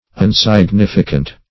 \Un`sig*nif"i*cant\